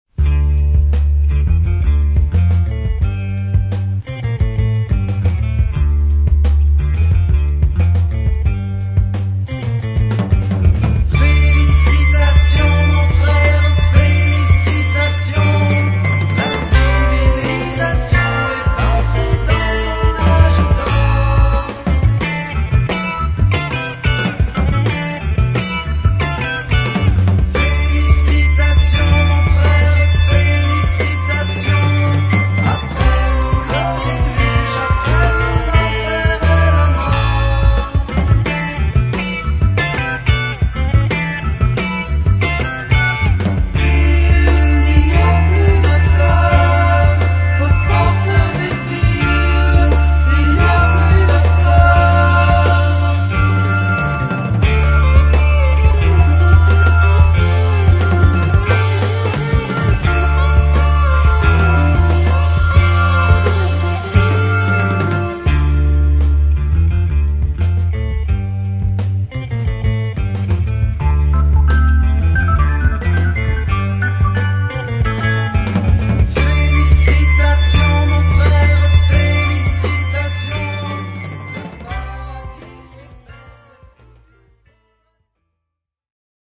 Super dope French prog 45.